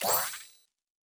Special & Powerup (24).wav